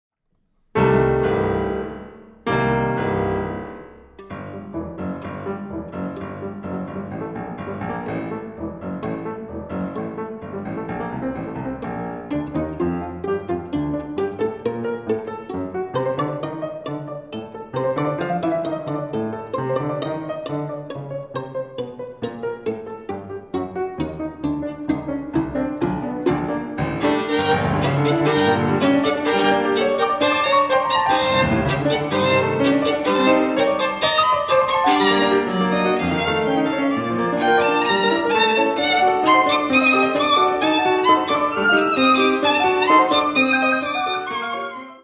Adagio sostenuto (5:27)
in 1917 in a late romantic style